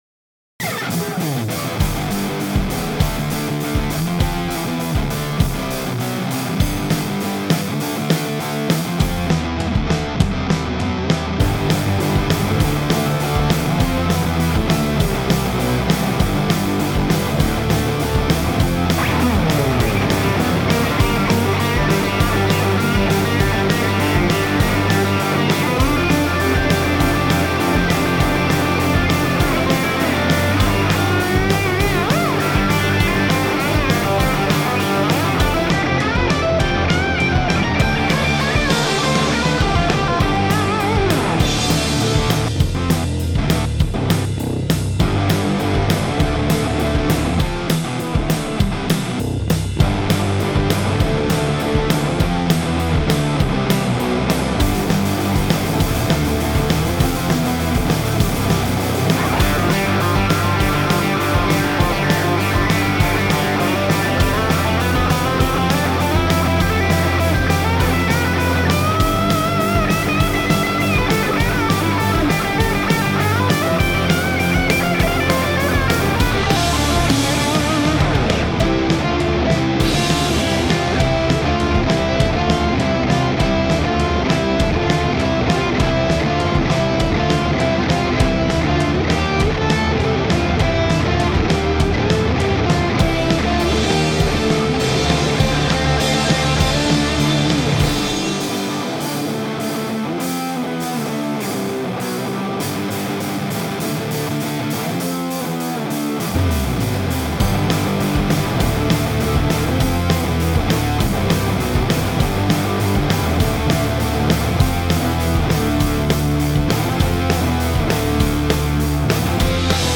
Genre: punk, rock.